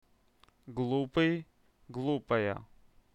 פרוש בעבריתהיגוי באנגליתלשמיעת המילה
טיפש - טיפשהGulupey - glupaya